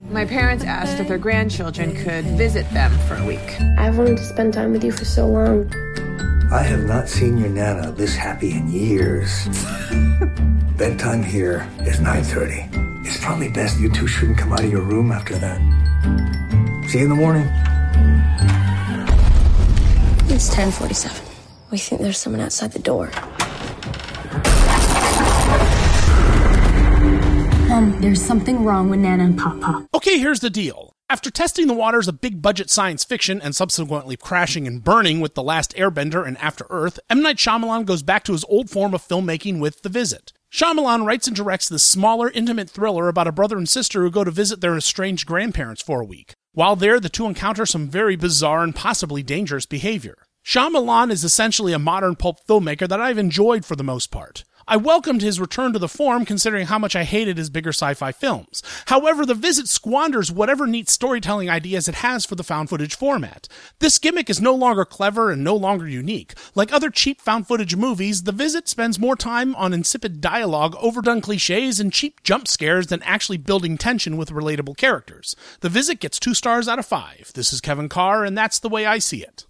‘The Visit’ Movie Review